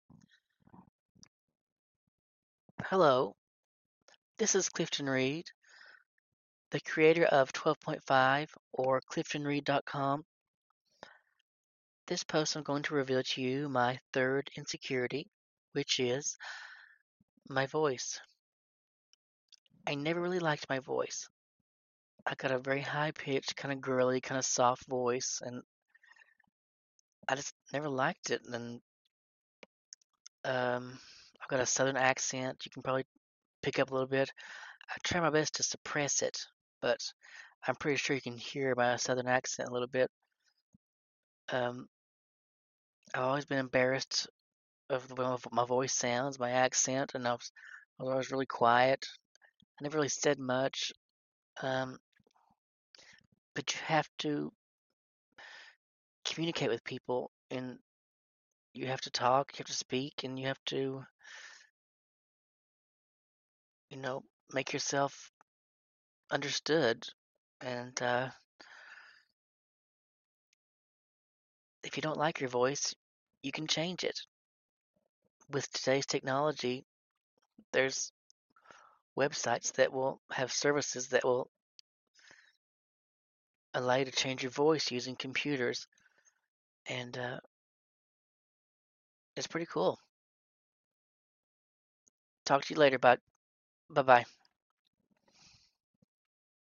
My real voice.